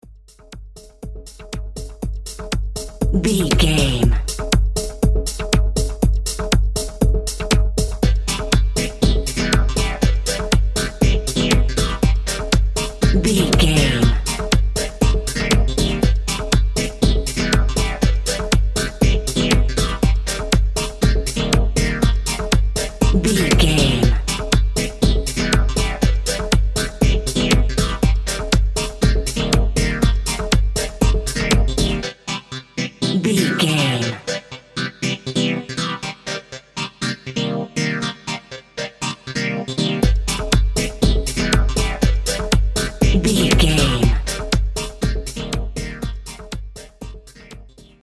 Groovy Electronic Dance Music Cue.
Aeolian/Minor
groovy
futuristic
drums
drum machine
synthesiser
house
techno
electro
synth lead
synth bass